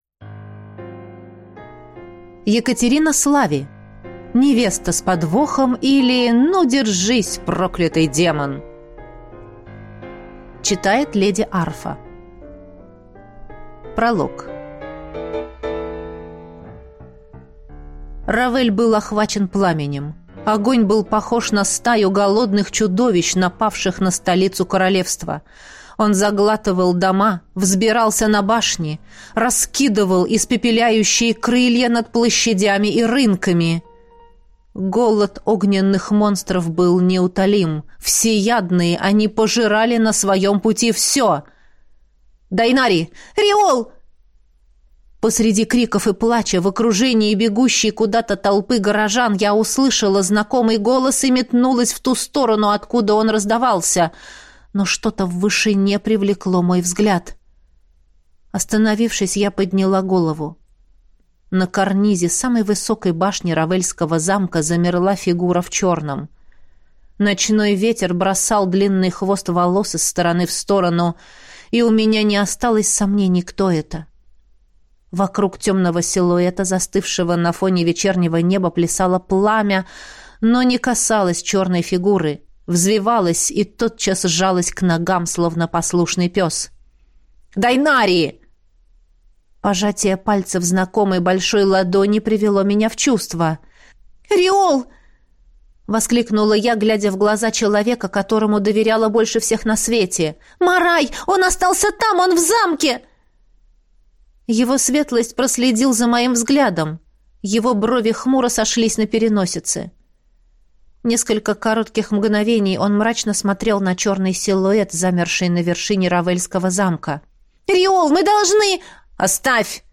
Аудиокнига Невеста с подвохом, или Ну, держись, проклятый демон!